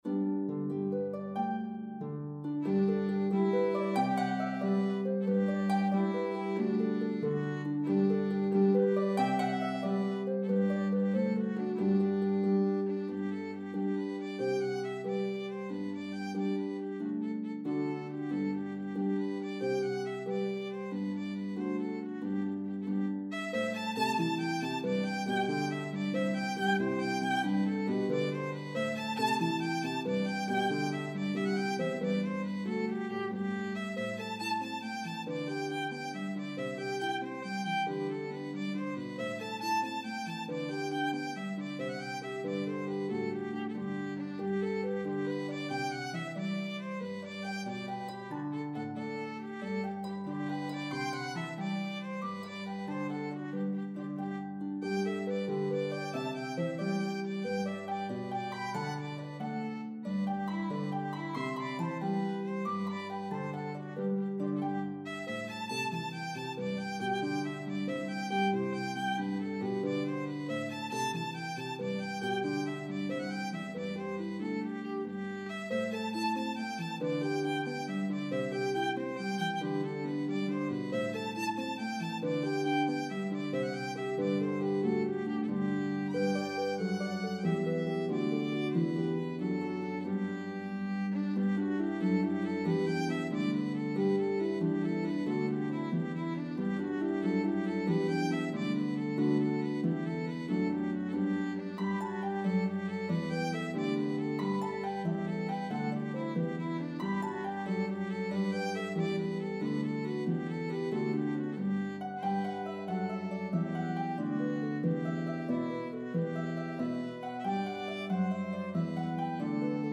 a medley of two joyful, upbeat Irish Jigs